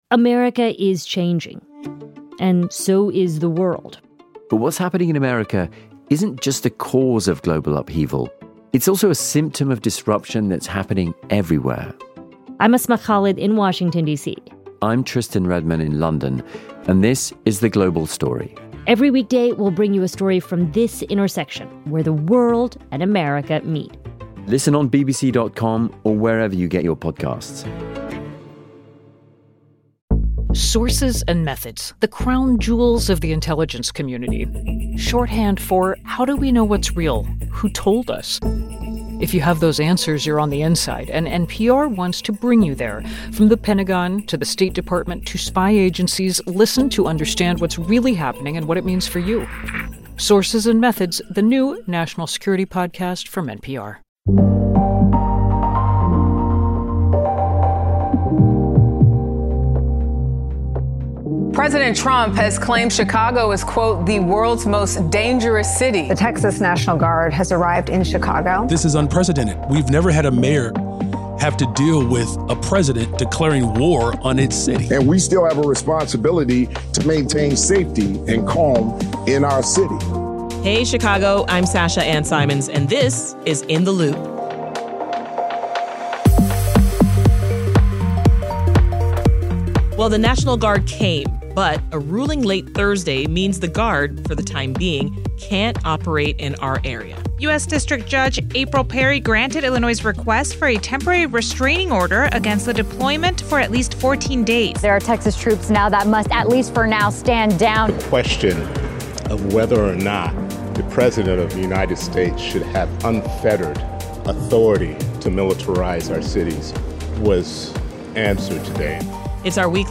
… continue reading 3141 حلقات # WBEZ Chicago # News Talk # News # On Covid19